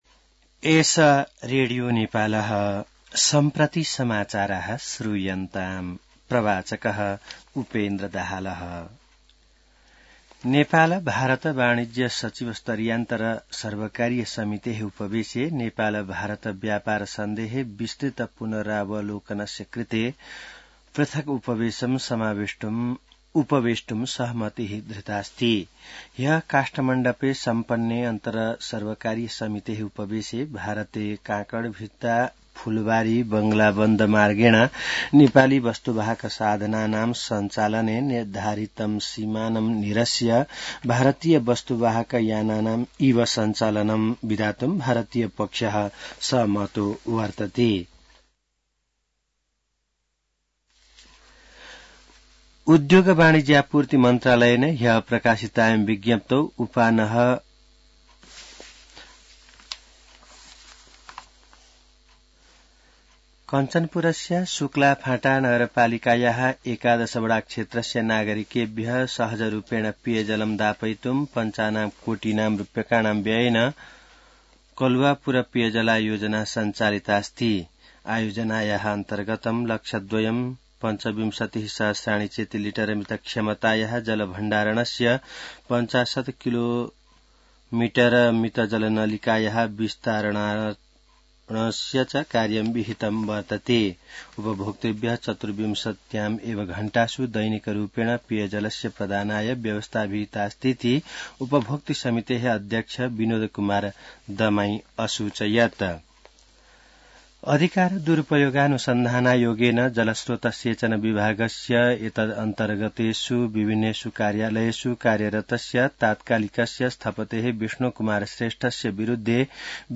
An online outlet of Nepal's national radio broadcaster
संस्कृत समाचार : १ माघ , २०८१